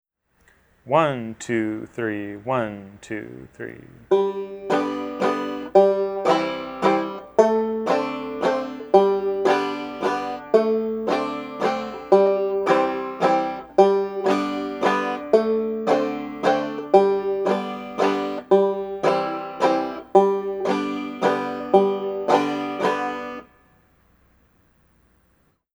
Voicing: Banjo